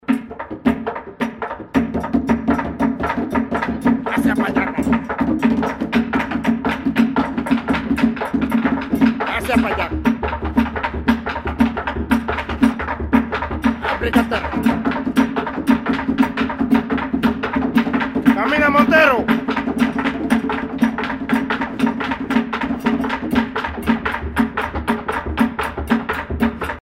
rythme d'accompagnement de chant responsorial